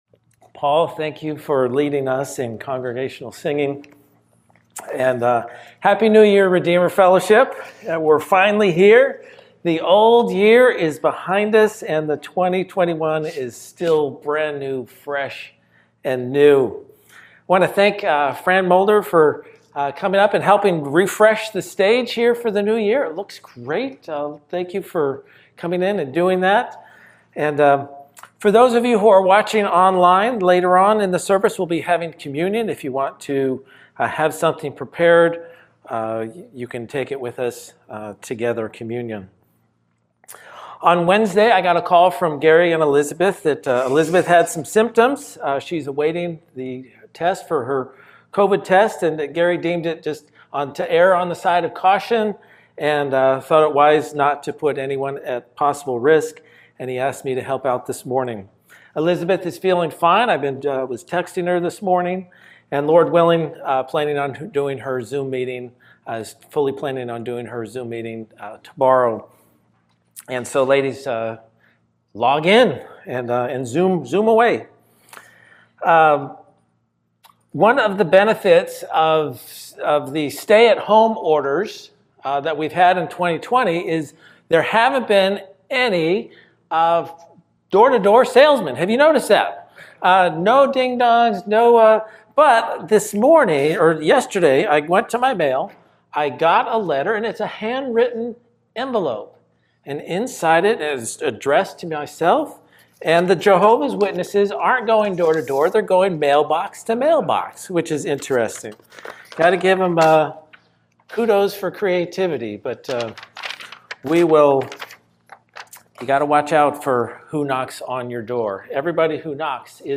Guest Speaker, Standalone Sermon